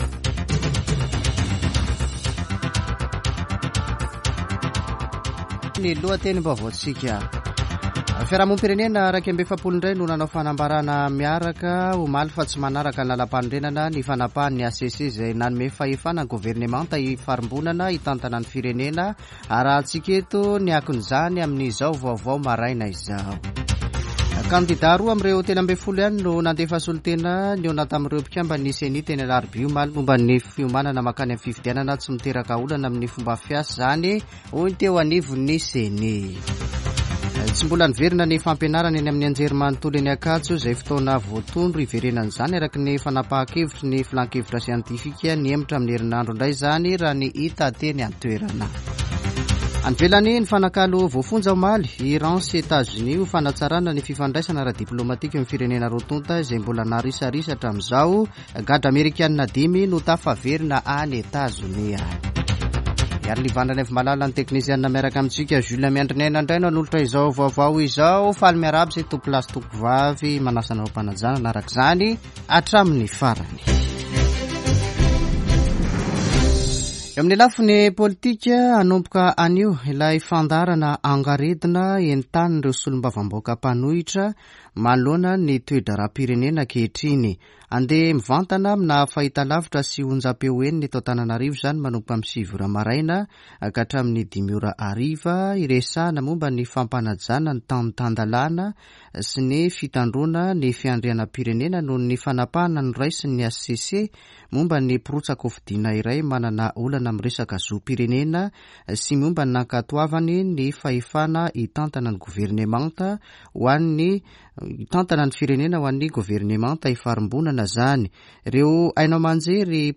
[Vaovao maraina] Talata 19 septambra 2023